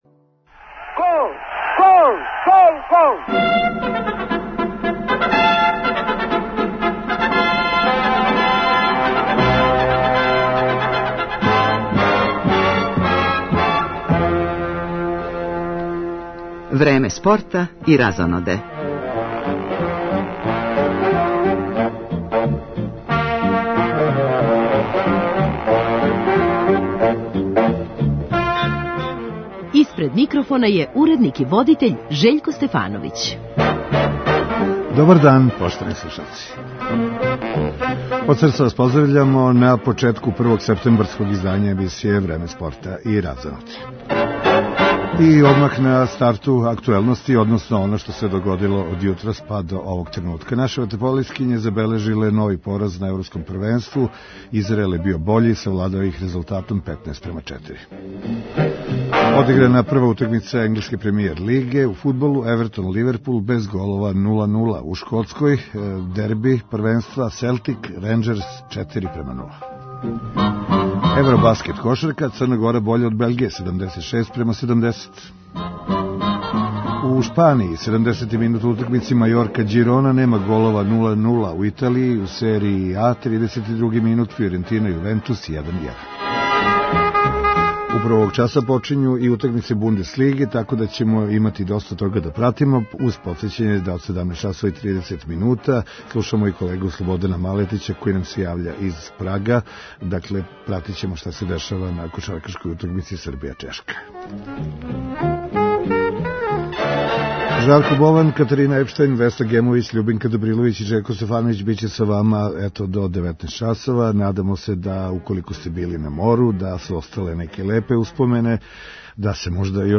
Чућемо и разговоре са атлетским дивама Иваном Вулетом и Адрианом Вилагош, изјаве наших најбољих одбојкаша уочи осмине финале Светског првенства у Гљивицама са селекцијом Аргентине и сазнати како је протекло гала вече у част једне од највећих српских спортских звезда свих времена Драгана Џајића, одржано синоћ у Народном позоришту.